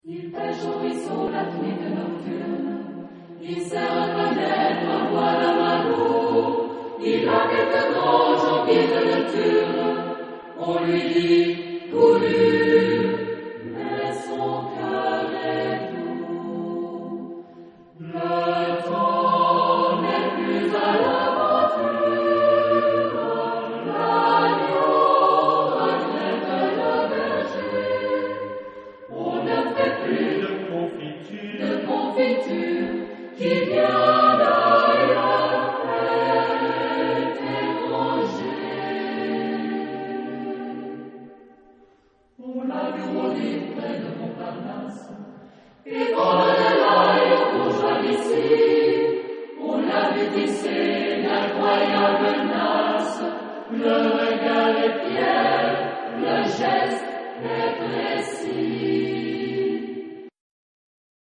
Género/Estilo/Forma: Profano ; Poema
Carácter de la pieza : popular (a la manera)
Tipo de formación coral: SATB  (4 voces Coro mixto )
Tonalidad : fa menor